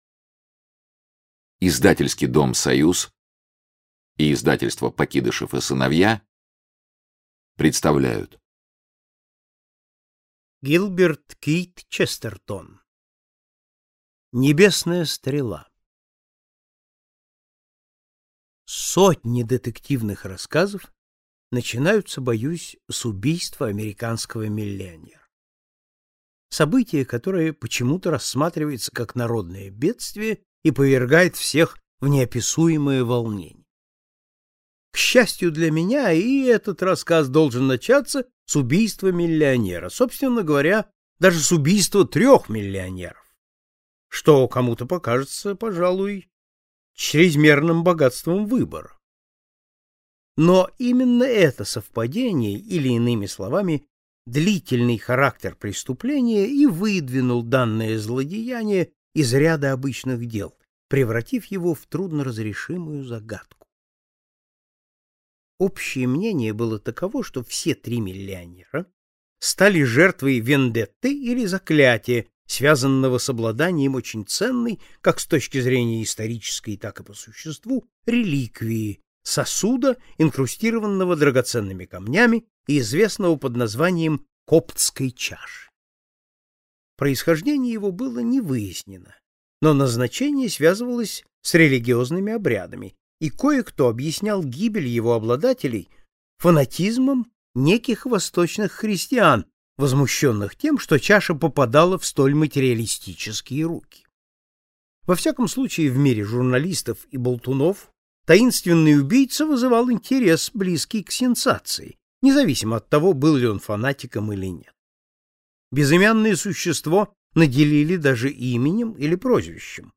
Аудиокнига Небесная стрела | Библиотека аудиокниг